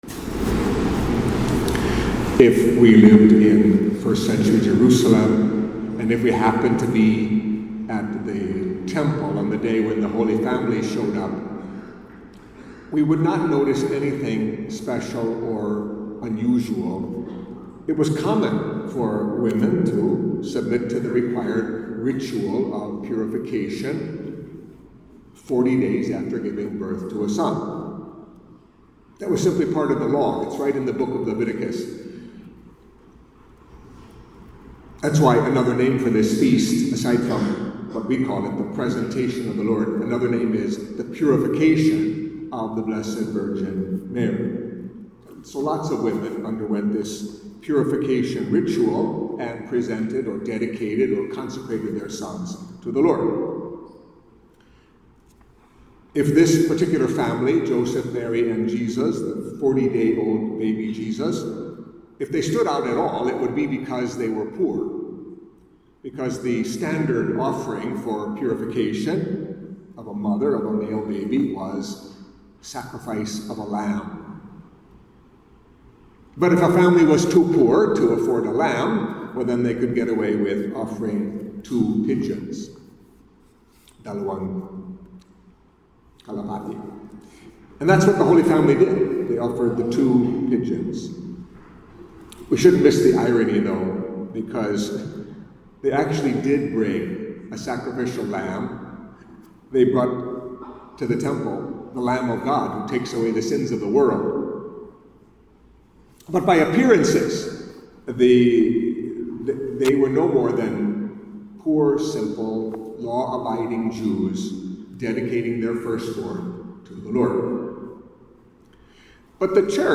Catholic Mass homily for the Feast of the Presentation of the Lord